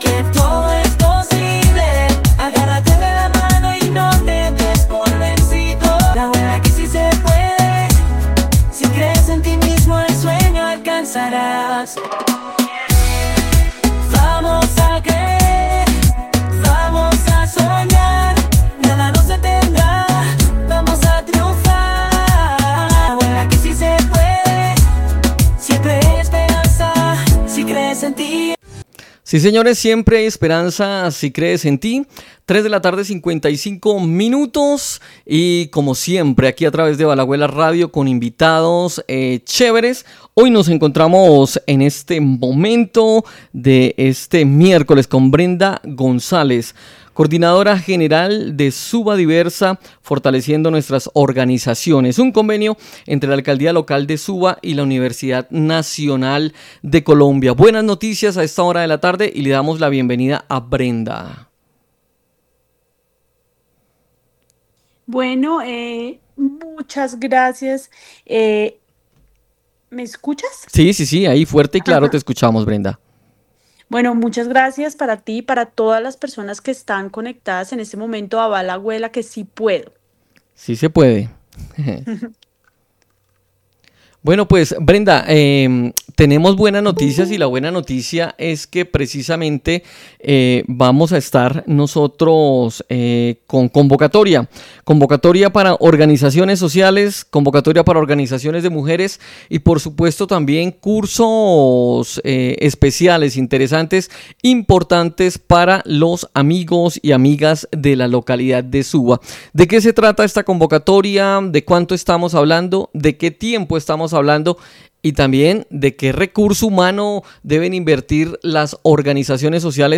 Entrevista
1entrevista-Suba-Diversa.mp3